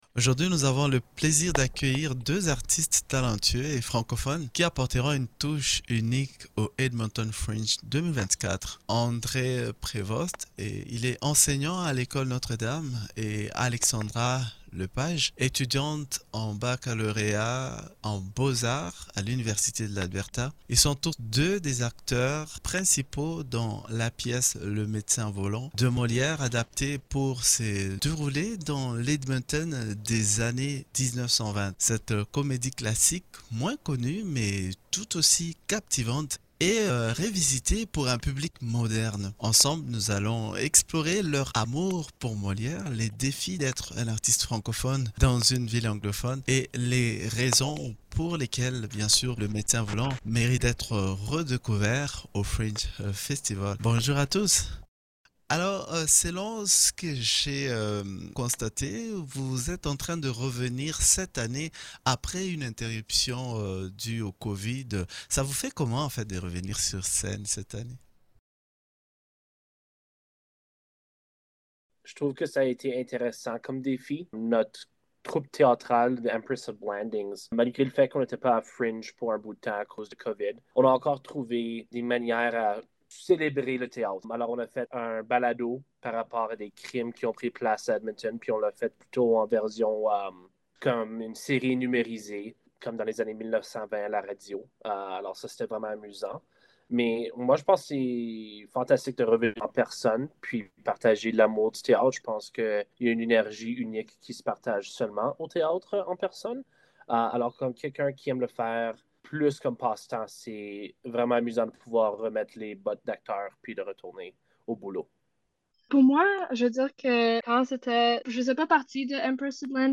Entrevue-gringe-festival-comediens_mixdown.mp3